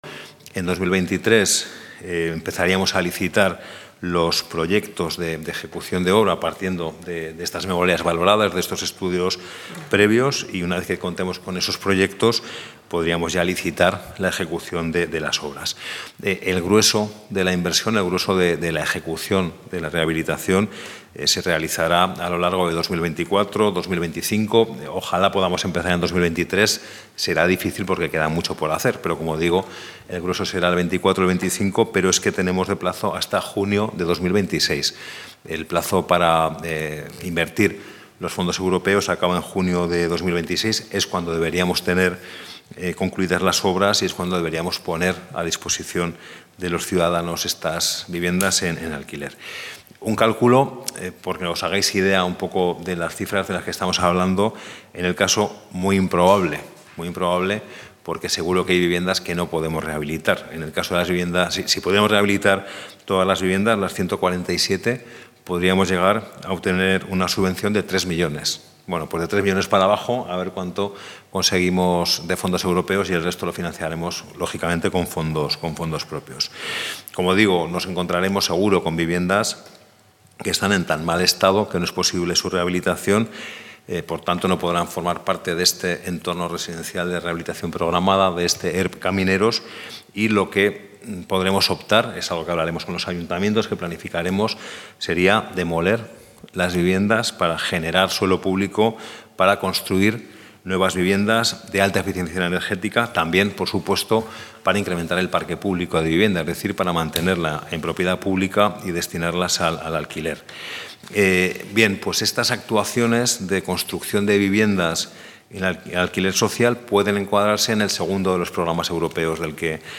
El consejero de Vertebración del Territorio, Movilidad y Vivienda, José Luis Soro, ha presentado en rueda de prensa el Programa Camineros, una iniciativa con la que se pretende actuar en las 147 viviendas vacías de 11 parques de maquinaria del Gobierno de Aragón para ampliar el parque público de vivienda de alquiler mediante Suelo y Vivienda, sociedad adscrita a CORPORACIÓN.